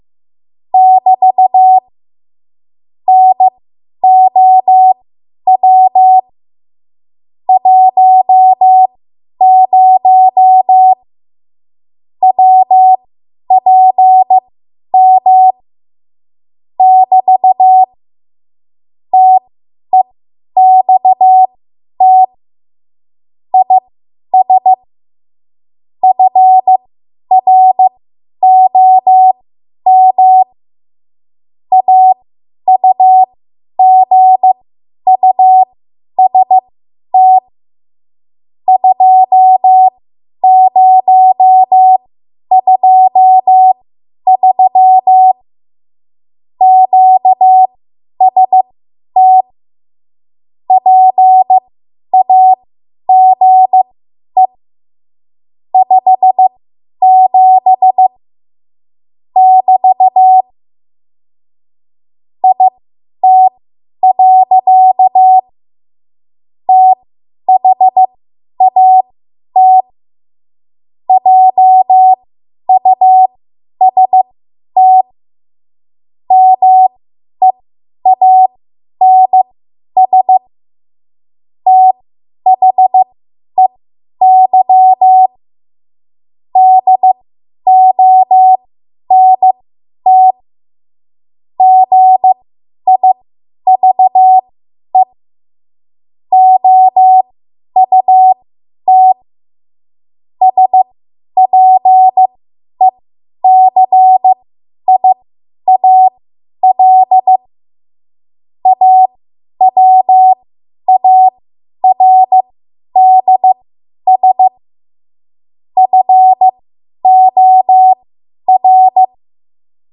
10 WPM Code Practice Archive Files
Listed here are archived 10 WPM W1AW code practice transmissions for the dates and speeds indicated.
You will hear these characters as regular Morse code prosigns or abbreviations.